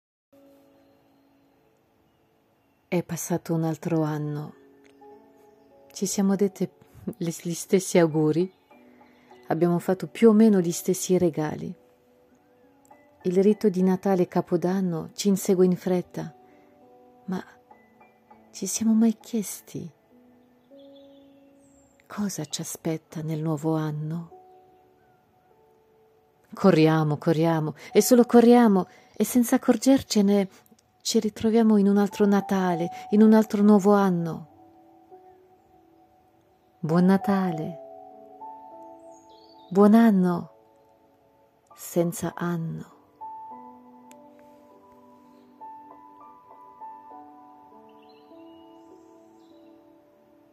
Ascolta dalla voce dell’attrice